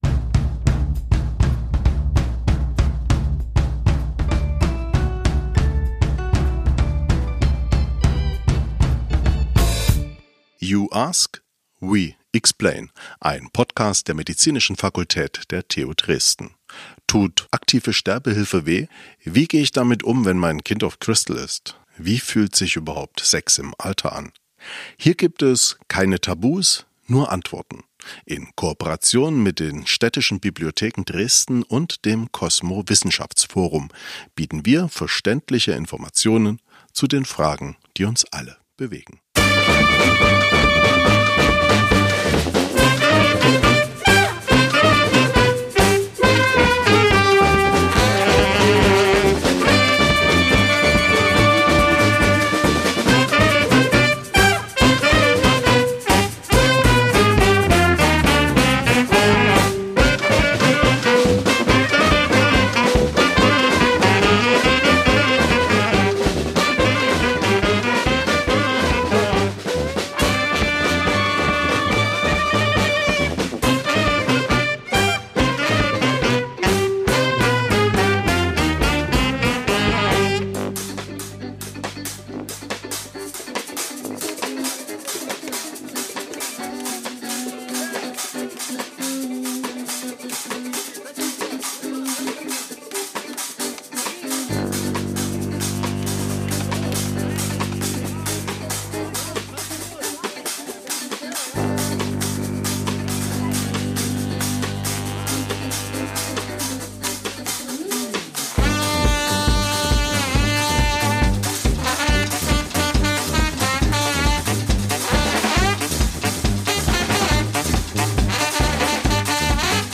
Es verspricht eine spannende Diskussion über Dresden, Europa und die Welt zu werden in der nächsten TUD Lectures+.